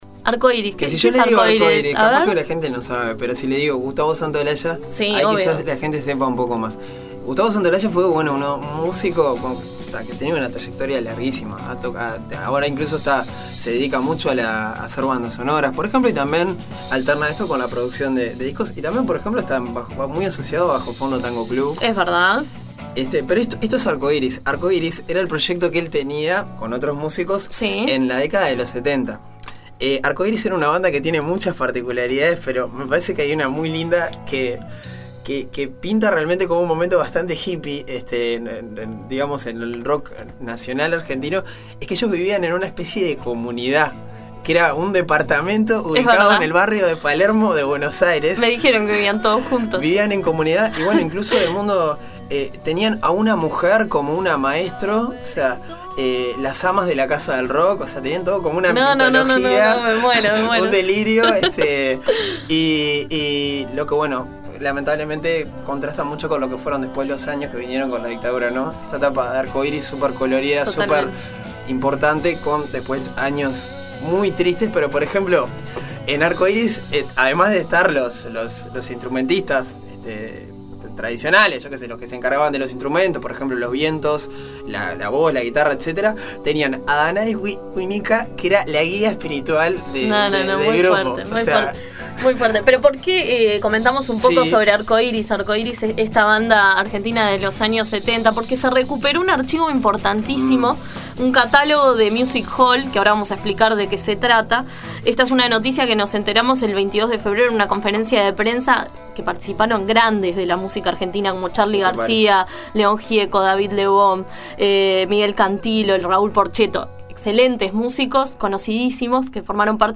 En La Mañana de Uni Radio conversamos con Diego Boris, presidente de INAMU que nos relató la importancia del acceso a este archivo.